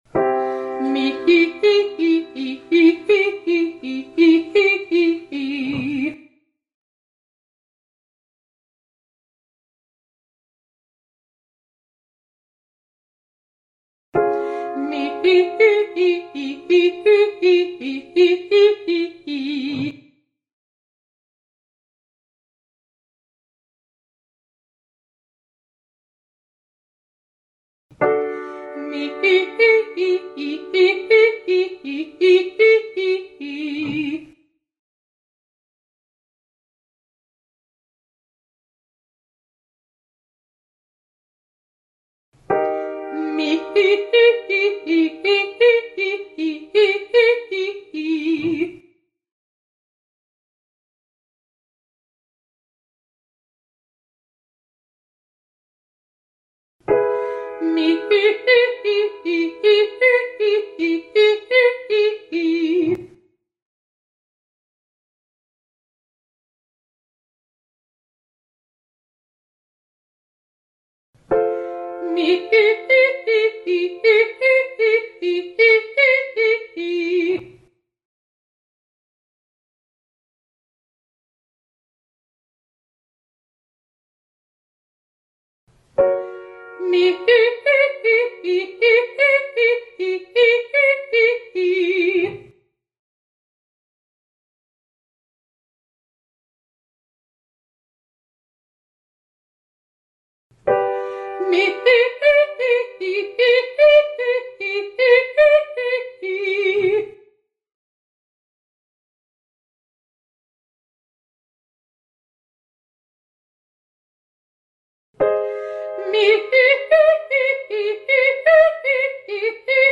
340: Triads Mee hee ascending
340: Mi hi 1 3 5 3 1 staccato ascending to high g
Vft-1340-Triads-Mee-Hee-Ascending.mp3